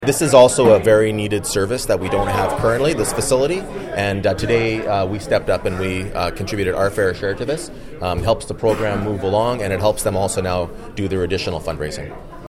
Mayor Panciuk tells Quinte News Belleville has a long history of supporting health care from doctor recruitment to help funding hospital foundations.